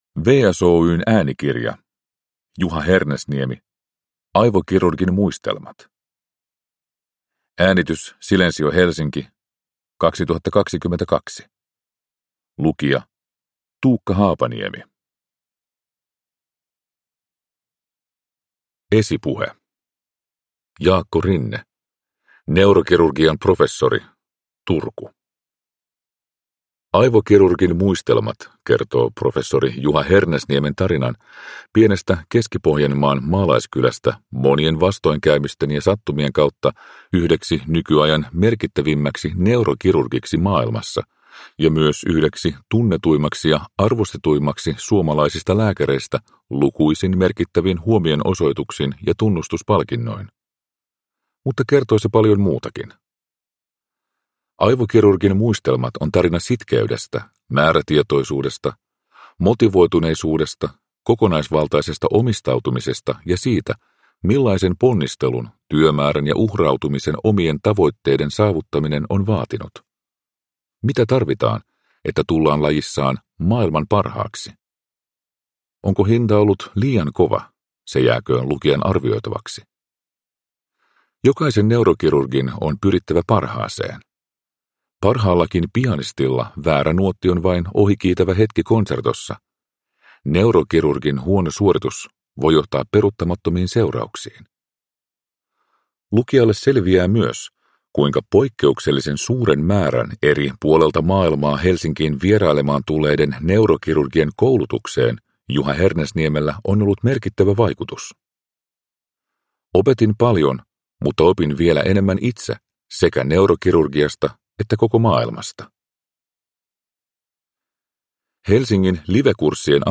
Aivokirurgin muistelmat – Ljudbok – Laddas ner